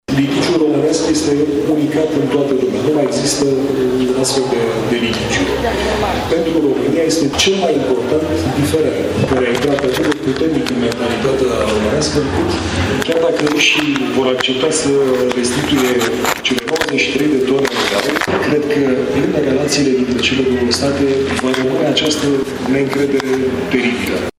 Litigiul dintre România şi Rusia privind tezaurul românesc, trimis la Moscova în 1916, pentru a fi în siguranţă în timpul Primului Război Mondial, este unic în lume. El nu poate fi soluţionat nici măcar de instanţele internaţionale, a afirmat duminică, la Bookfest Tîrgu Mureş jurnalistul Marian Voicu, cu ocazia lansării volumului său ”Tezaurul României de la Moscova”.
stiri-24-sept-voicu-bookfest.mp3